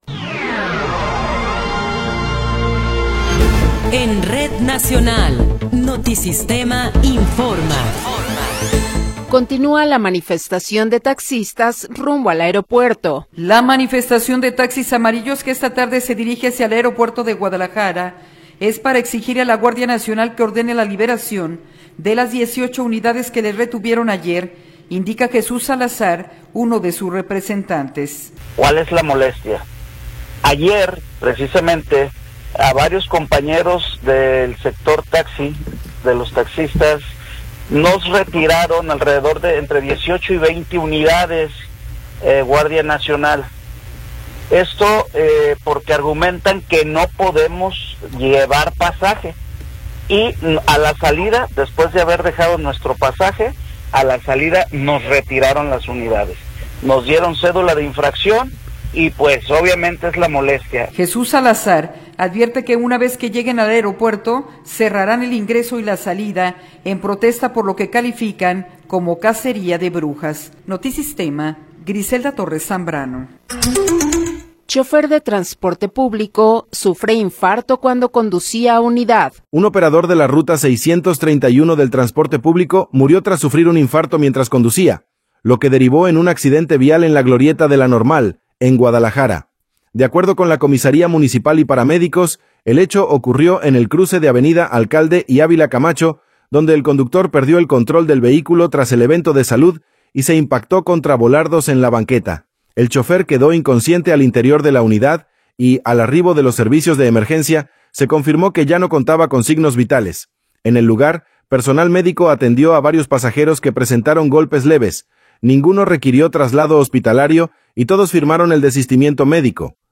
Noticiero 15 hrs. – 24 de Abril de 2026